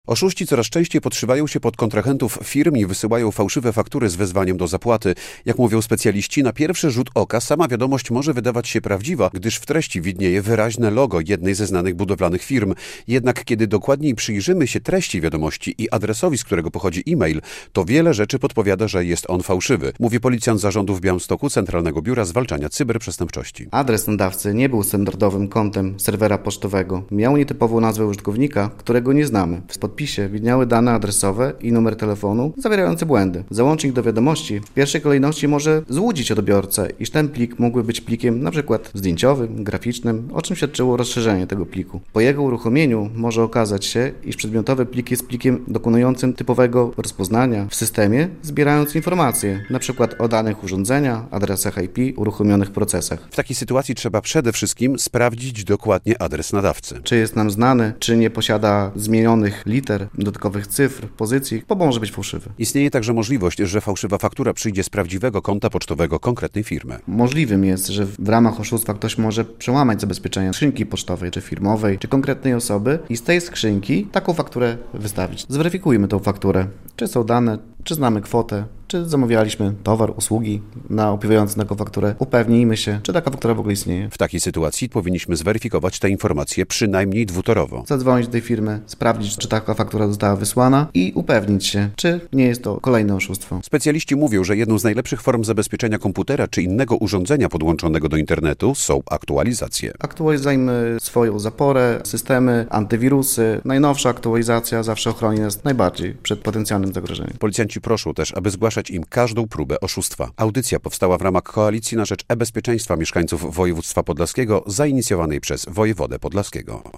Kiedy przestępcy złamią zabezpieczenia pocztowe, to istnieje taka możliwość, że dostaniemy fałszywą fakturę, ale z prawdziwego konta pocztowego firmy - wyjaśnia policjant.